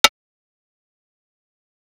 ATR Snare (50).wav